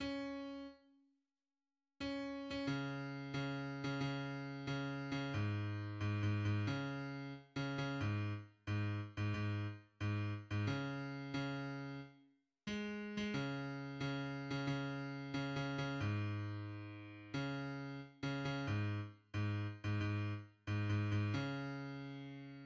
{\clef bass \tempo 4=90\key fis \major \set Score.currentBarNumber = #18 \bar "" cis' r2 cis'8. cis'16 cis4 cis8. cis16 cis4 \tuplet 3/2{cis cis8} gis,4 \tuplet 3/2{gis,8 gis, gis, }cis4 \tuplet 3/2{r8 cis cis} gis, r gis, r16 gis,16 gis,8 r gis, r16 gis,16 cis4 cis r gis8. gis16 cis4 cis8. cis16 cis4 \tuplet 3/2{cis8 cis cis} gis,2 cis4 \tuplet 3/2{r8 cis cis} gis, r gis, r16 gis,16 gis,8 r \tuplet 3/2{gis, gis, gis,} cis2 }\addlyrics {\set fontSize = #-2 - Harf- n gol- de- ne fun di ne- vi- i - - im Far- vos hengt ir un shvaygt af di ver- bes?